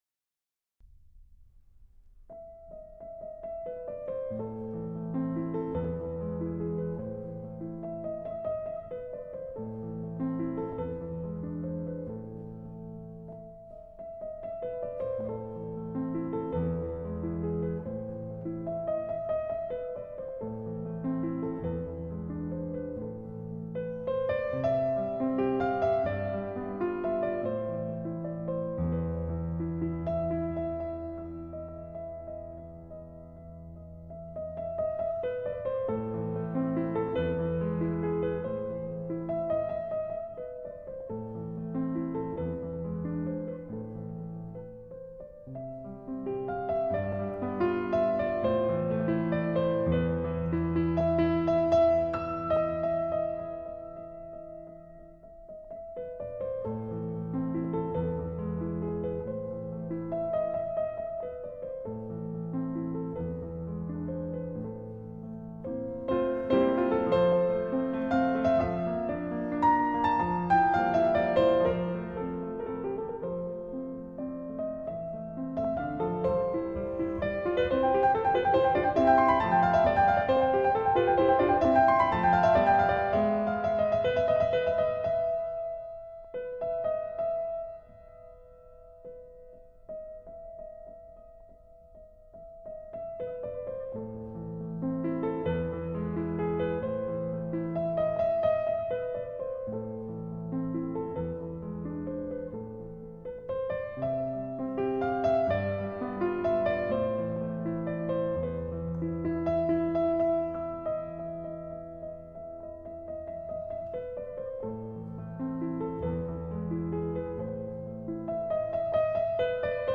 Bagatelle No. 25 in A Minor by Beethoven, performed by Lang Lang: